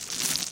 painting1.ogg